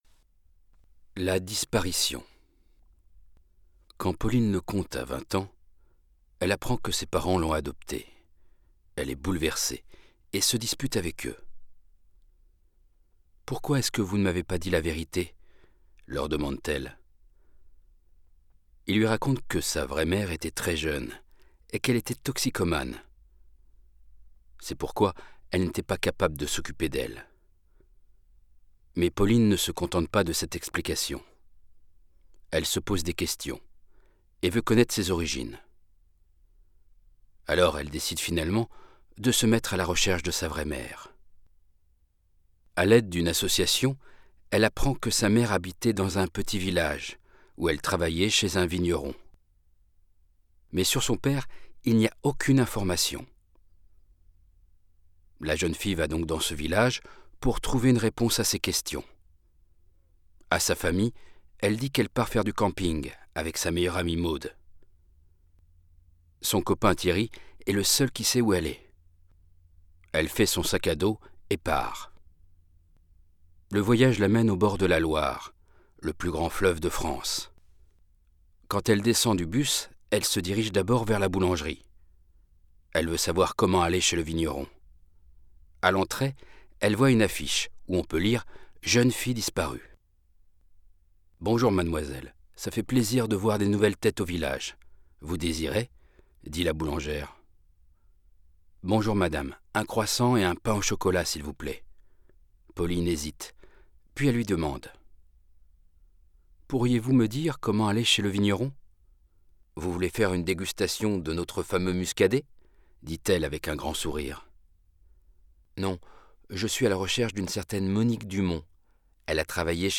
Spannende Kurzkrimis zum Sprachenlernen. - Vorgelesen von einem muttersprachlichen Profi-Sprecher in einem für den Lerner passenden Tempo.